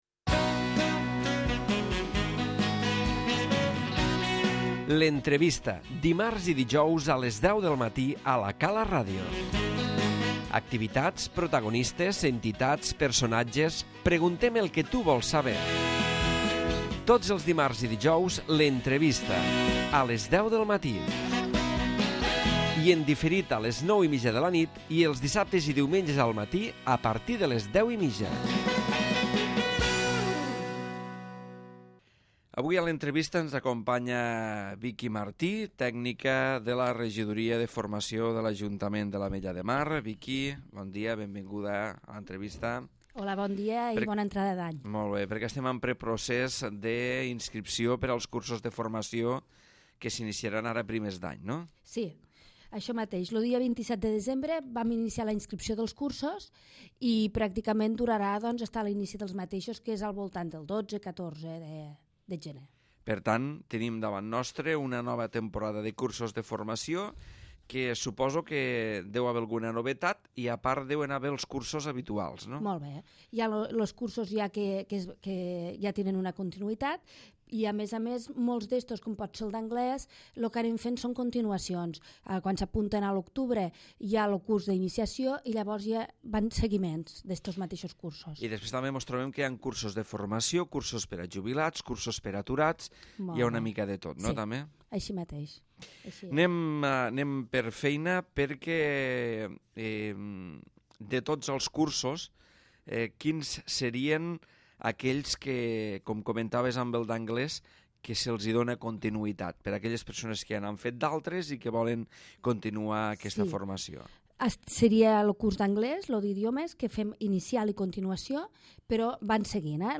L'Entrevista - Cursos Hivern 2013